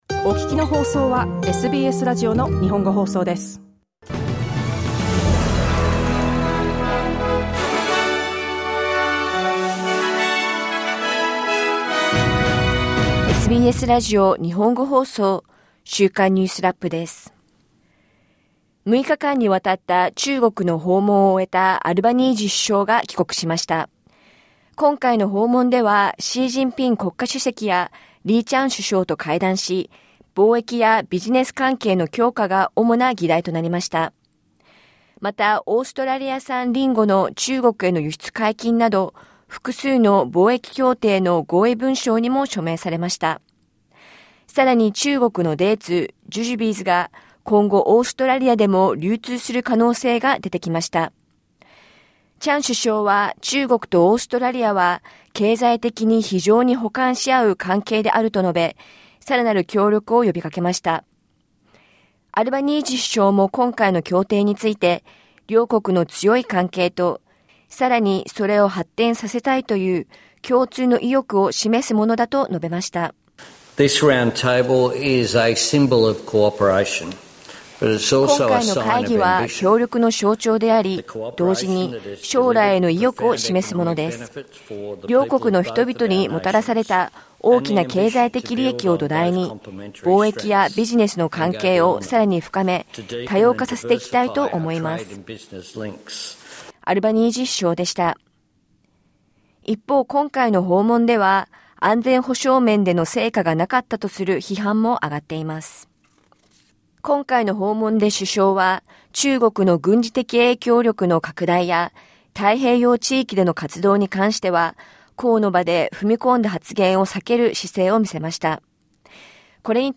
1週間を振り返るニュースラップです。